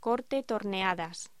Locución: Corte torneadas
voz